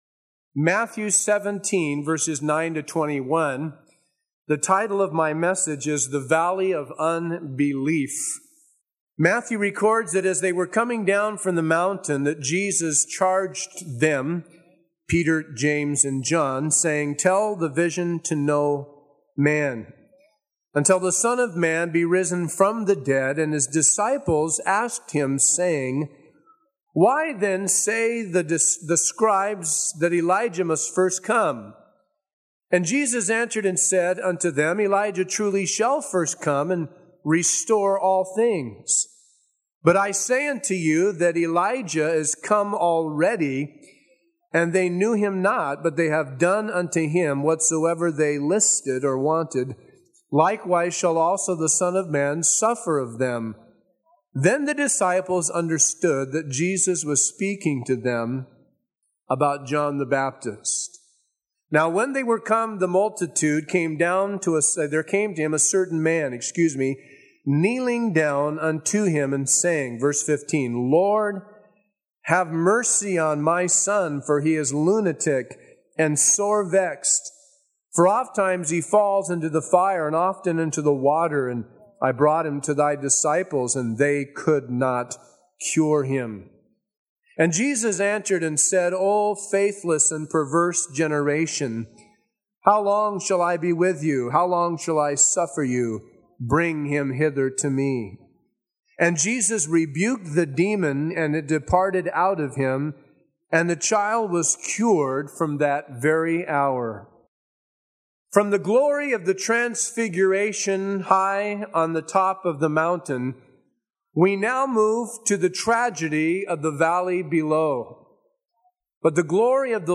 A verse-by-verse expository sermon through Matthew 17:9-21